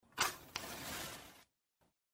FOSFORO CERILLA
Tonos EFECTO DE SONIDO DE AMBIENTE de FOSFORO CERILLA
Fosforo_-_Cerilla.mp3